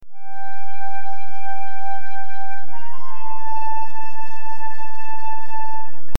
Sound sample: Flute Loop Dm 160 bpm
DOWNLOAD: Flute loop in Dm at 160 bpm (WAV file)
Relevant for: jazz,, flutes,, woodwinds,, orchestra.
Try preview above (pink tone added for copyright).
Tags: flute , symphony , loop , loops , woodwind